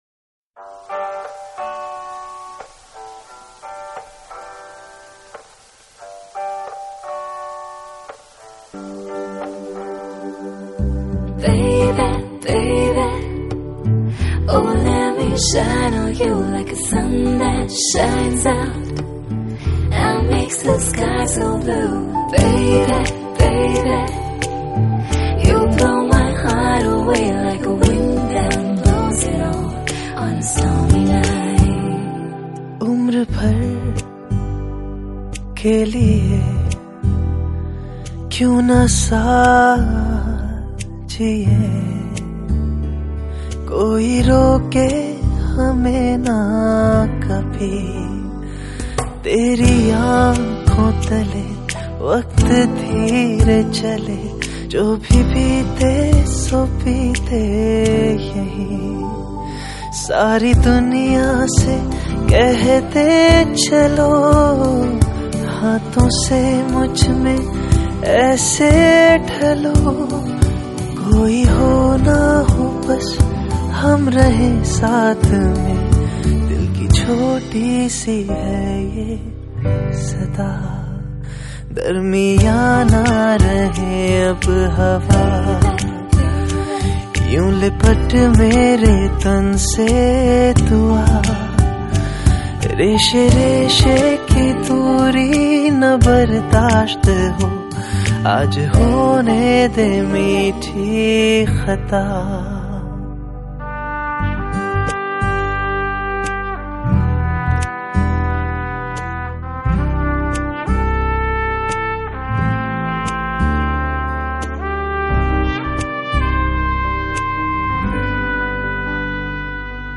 Bollywood Mp3 Music 2017